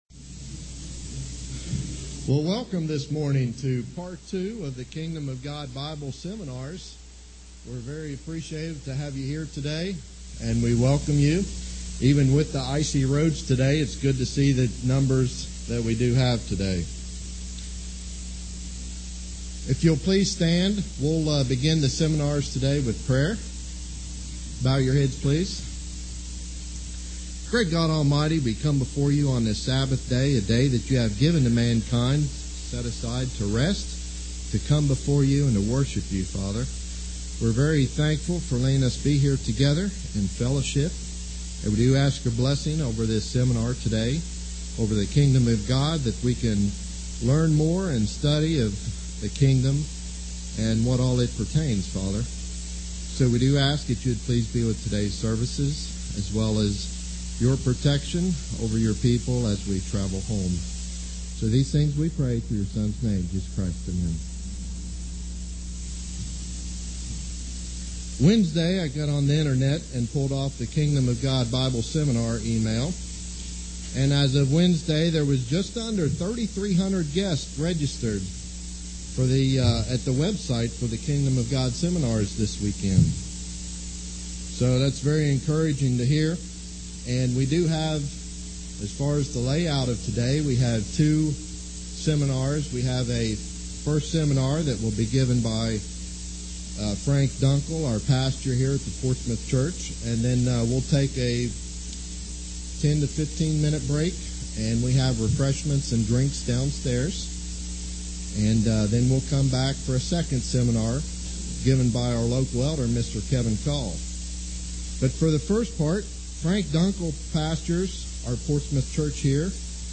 So why is the world today a messed up place, and what is coming next? Learn more in this Kingdom of God seminar.